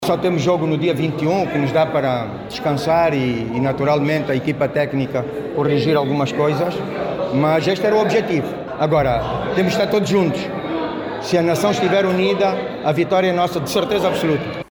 O Ministro da Juventude e Desportos, Rui Falcão Pinto de Andrade, deixou palavras de confiança após a fase regular, sublinhando que unidos conseguiremos ir até ao fim.